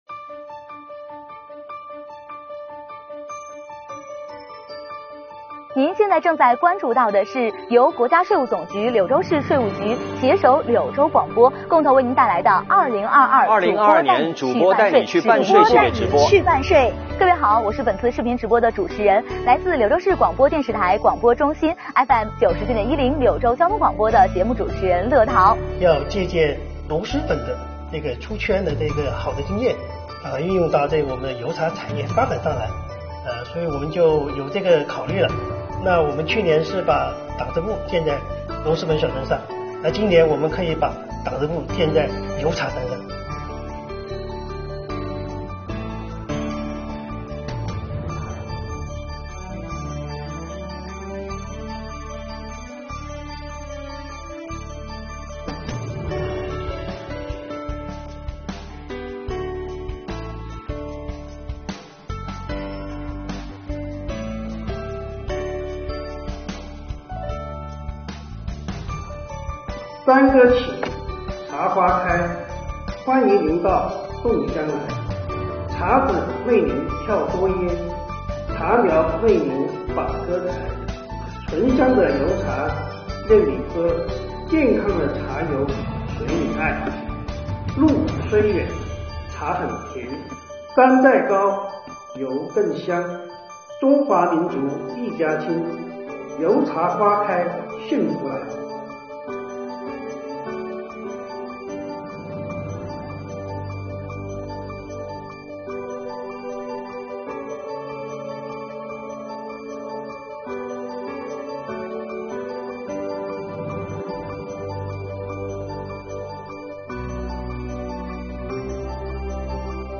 柳州广播主播团队
党委委员、副局长陈晓筠在现场致辞